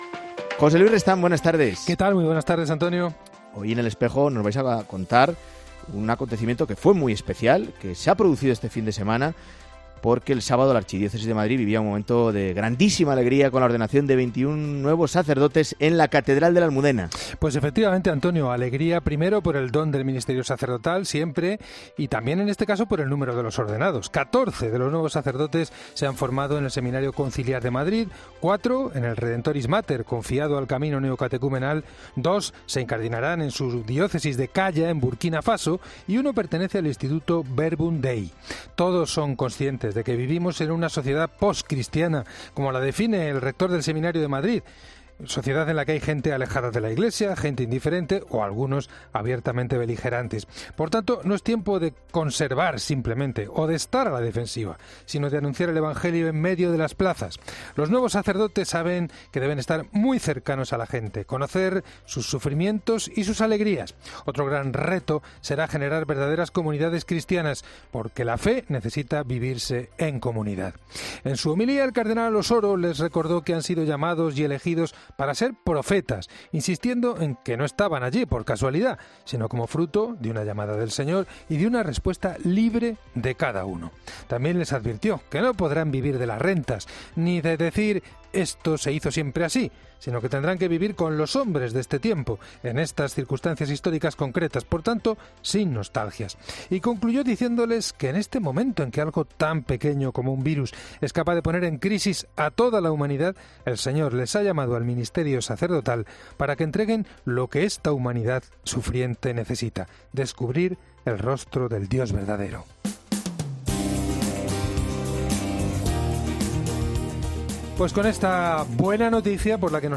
En Espejo 22 junio 2020: Entrevista a mons. Alfonso Carrasco, obispo de Lugo